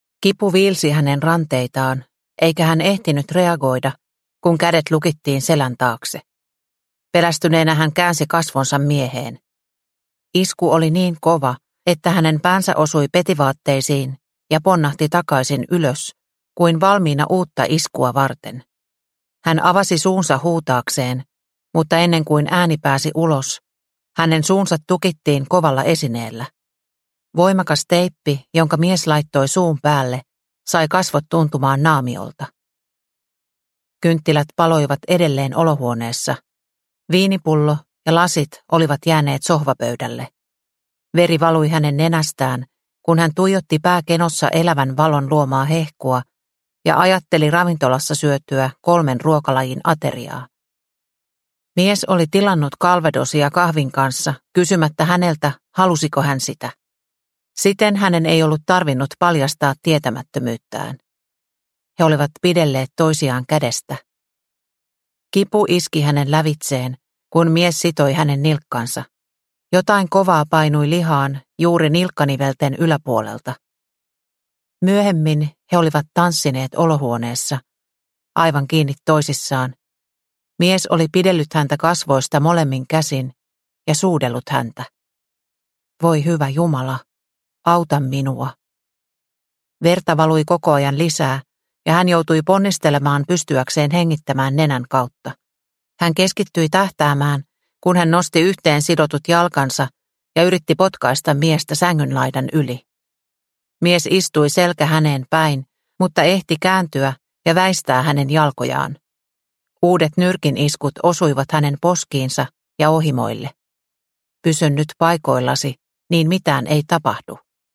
Nimimerkki Prinsessa – Ljudbok – Laddas ner